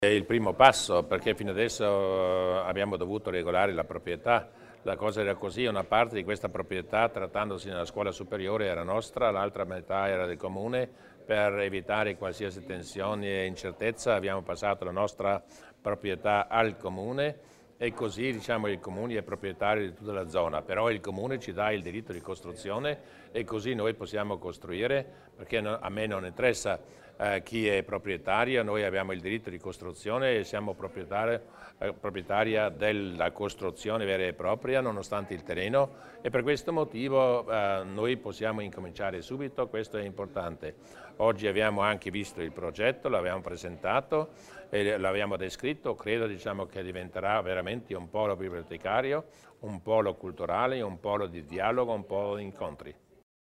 Il Presidente Durnwalder spiega l'importanza del nuovo polo